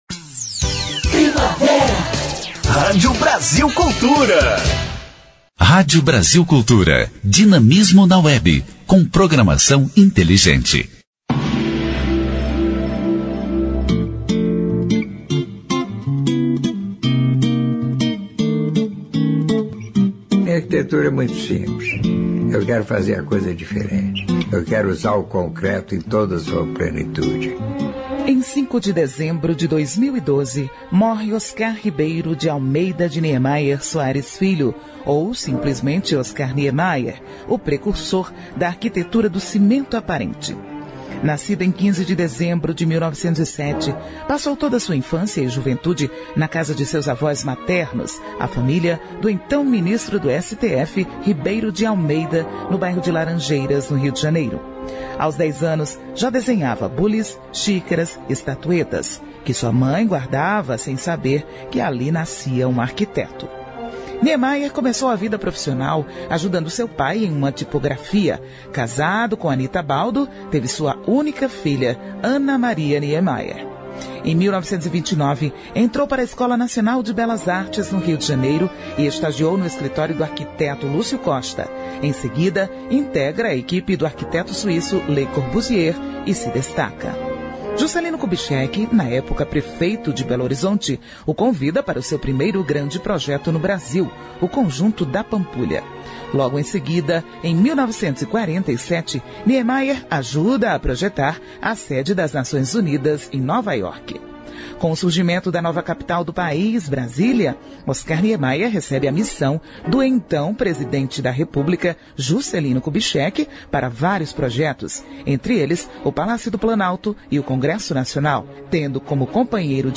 História Hoje: Programete sobre fatos históricos relacionados às datas do calendário.